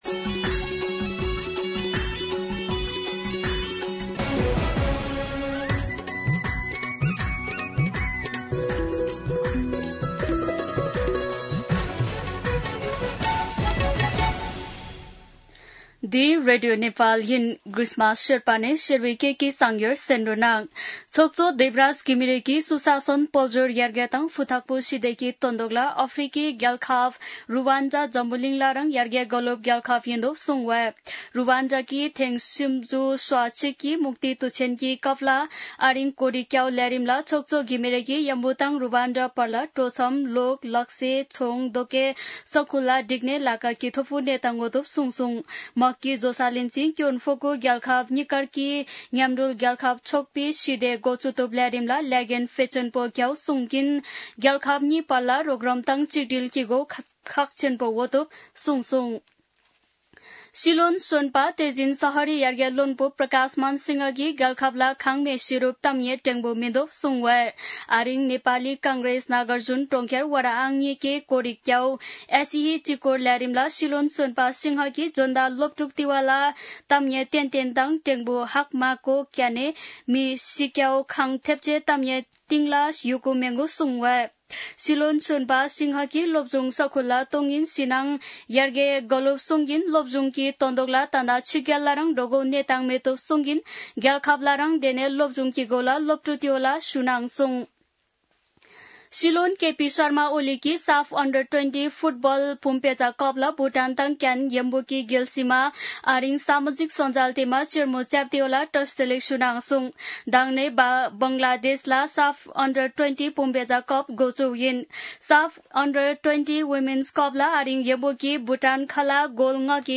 शेर्पा भाषाको समाचार : २८ असार , २०८२
Sherpa-News-.mp3